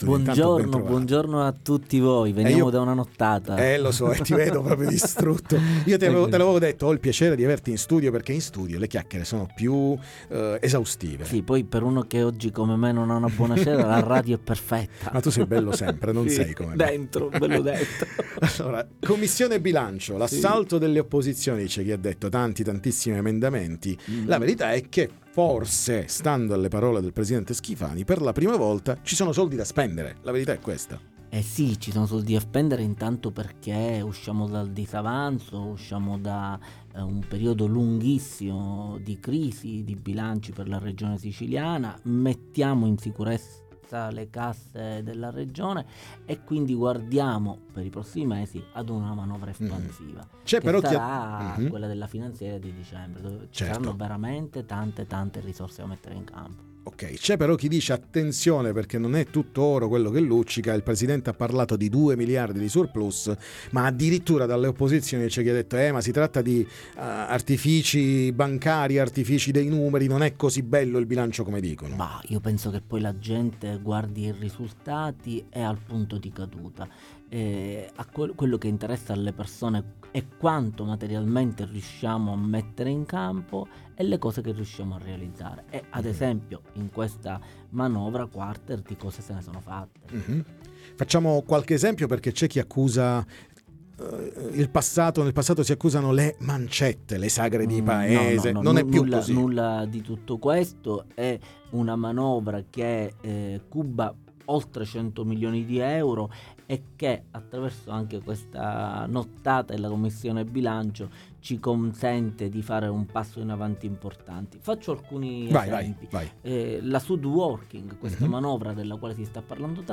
Commissione Bilancio Comune di Palermo, ne parliamo con Vincenzo Figuccia